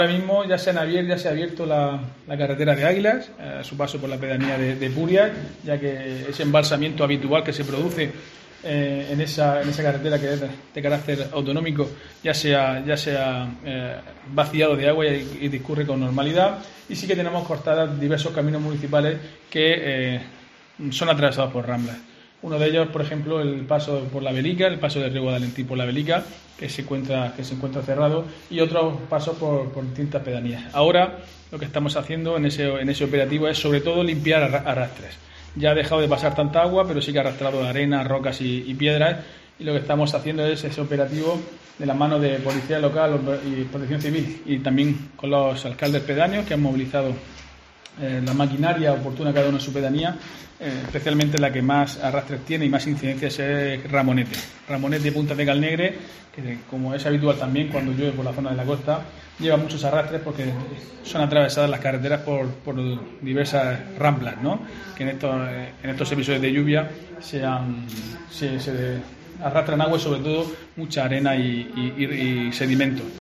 Diego José Mateos, alcalde de Lorca